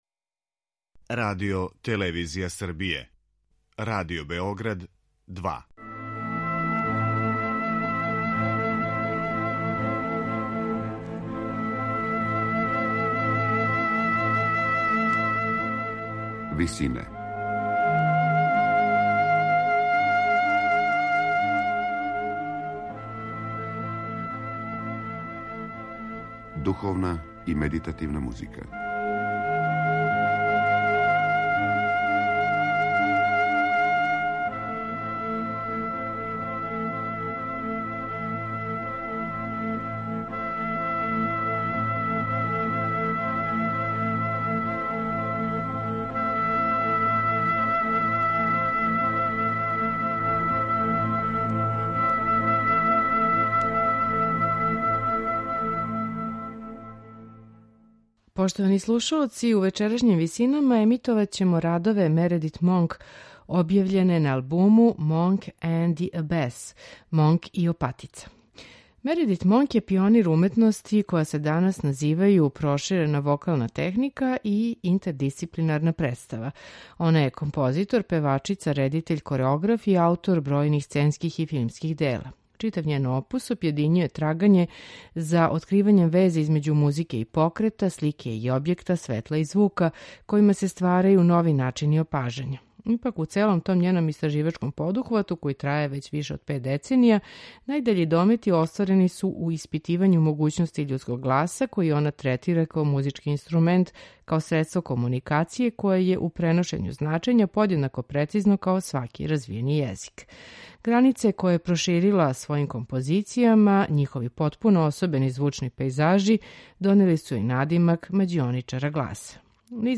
за вокални ансамбл